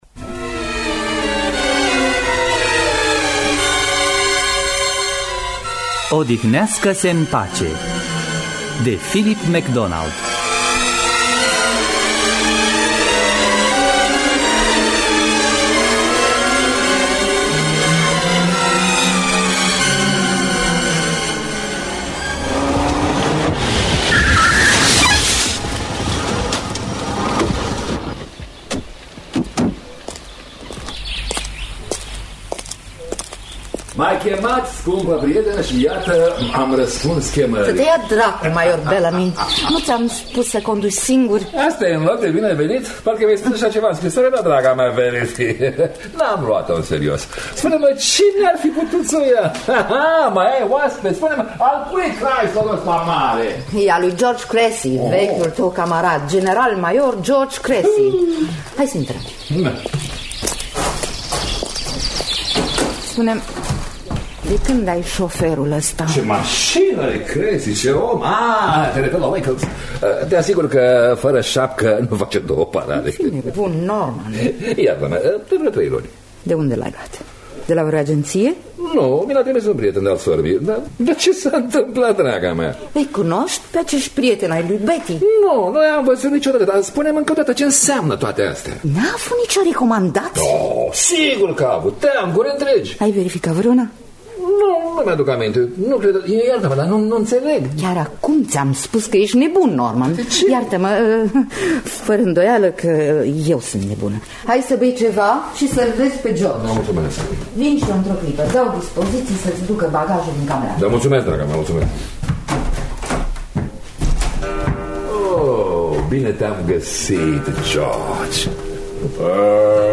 “Odihnească-se în pace!” de Philip MacDonald – Teatru Radiofonic Online